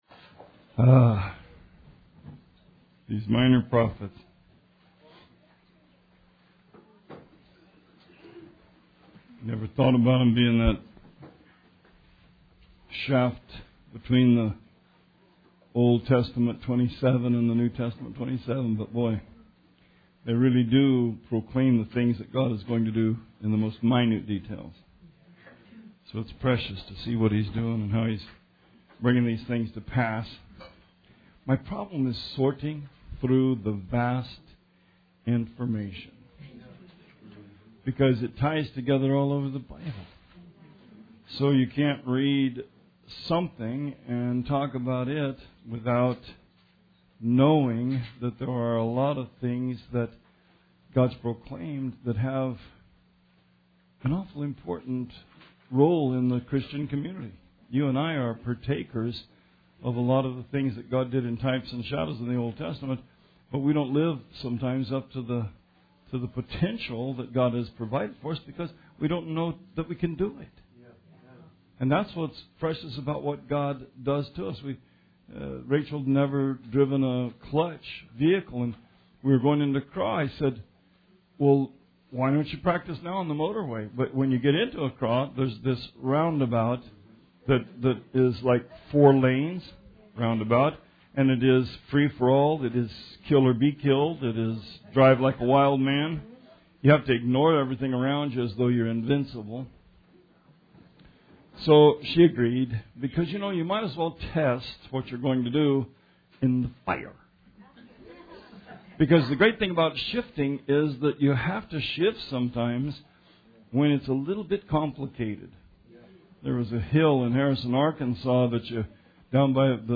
Sermon 1/28/18